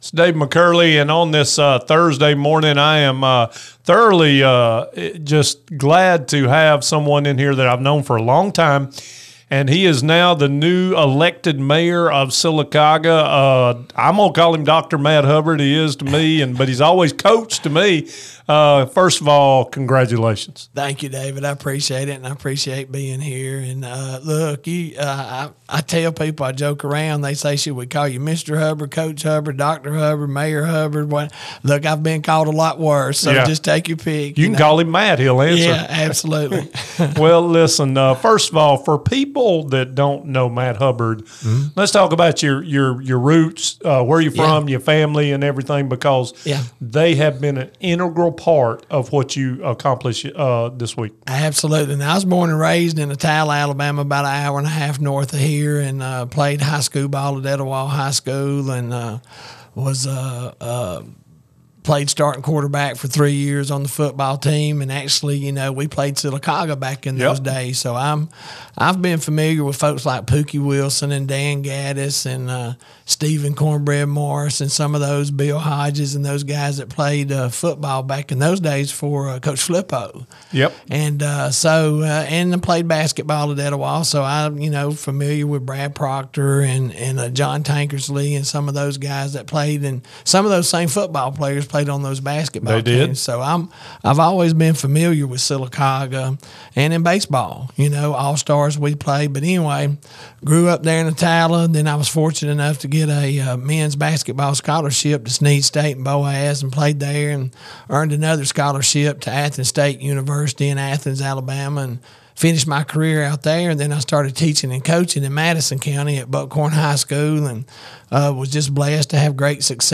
Interview with Newly Elected Sylacauga Mayor Matt Hubbard